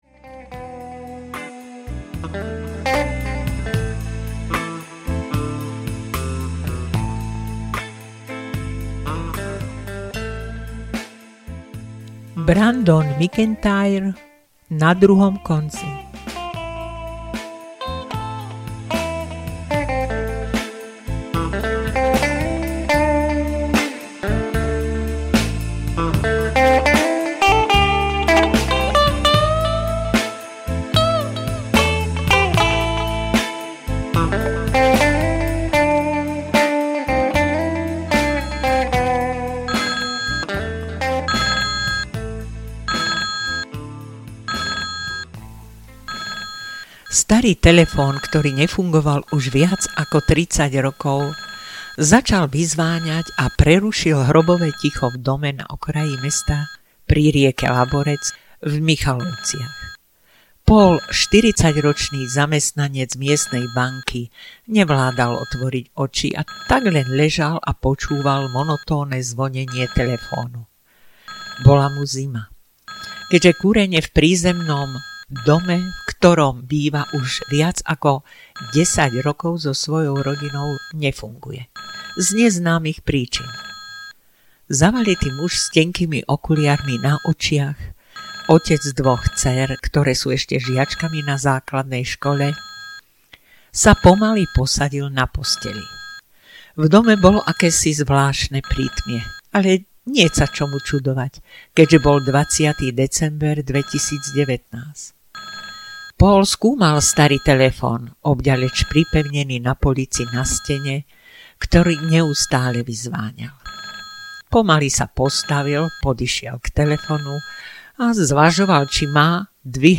Ukázka z knihy
na-druhom-konci-audiokniha